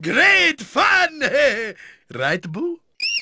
vs_frminscm_haha.wav